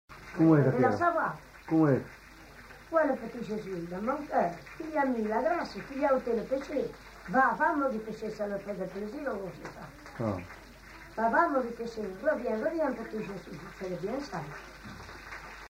Lieu : Puylausic
Genre : conte-légende-récit
Type de voix : voix de femme
Production du son : parlé
Classification : prière